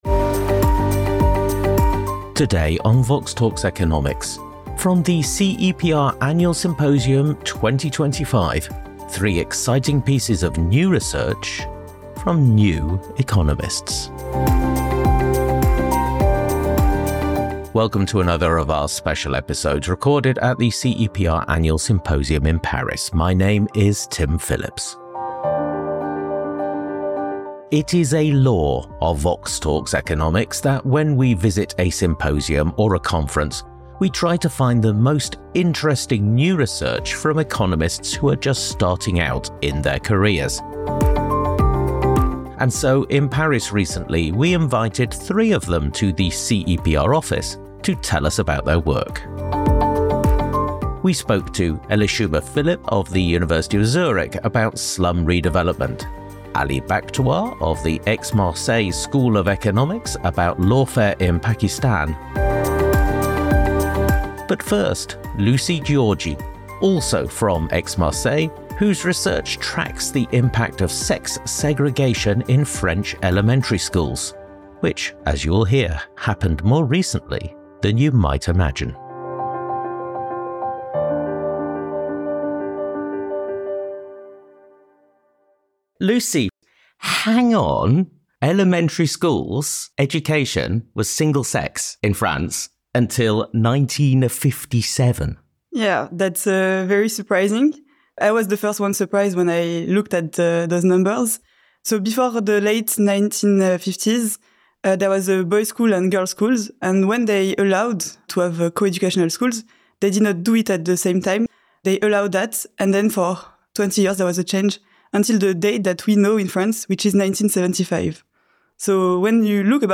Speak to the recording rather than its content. Recorded live at the CEPR Annual Symposium in Paris.